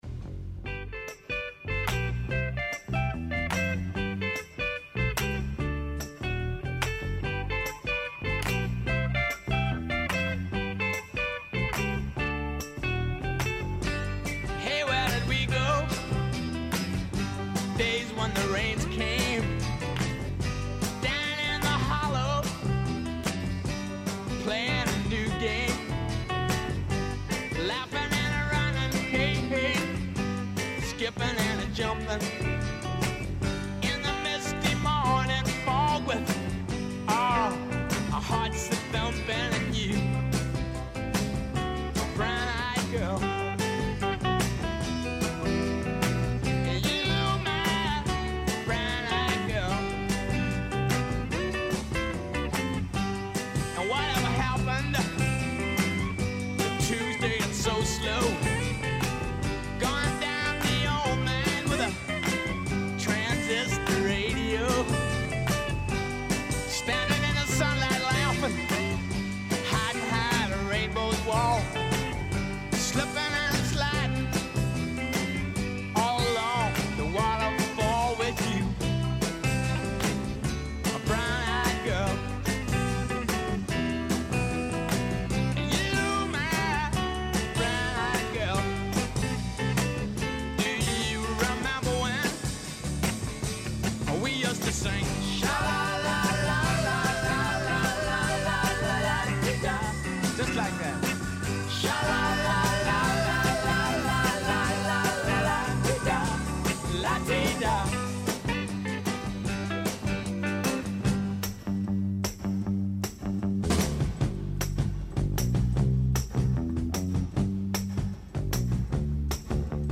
У данашњој емисији поред разних занимљивости слушаћете и датумске песме које нас подсећају на рођендане музичара, као и годишњице објављивања албума, синглова и других значајних догађаја из историје попа и рокенрола.
Ту је и пола сата резервисано само за музику из Србије и региона, а упућујемо вас и на нумере које су актуелне.